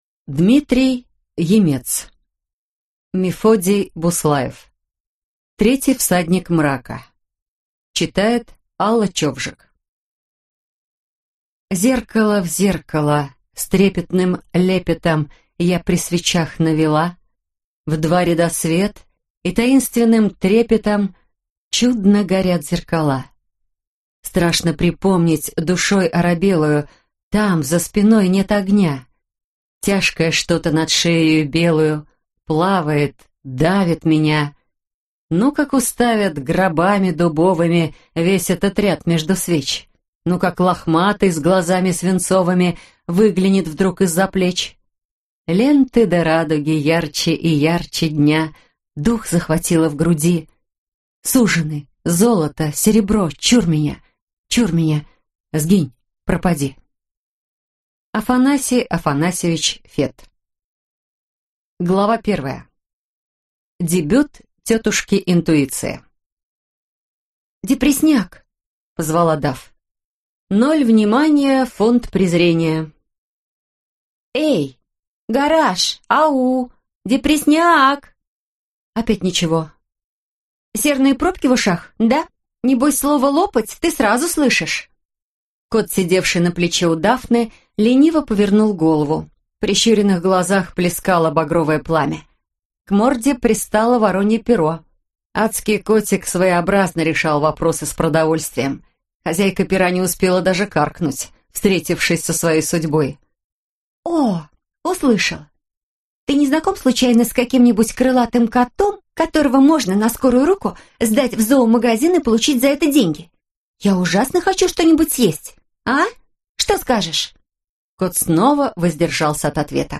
Аудиокнига Третий Всадник мрака | Библиотека аудиокниг
Прослушать и бесплатно скачать фрагмент аудиокниги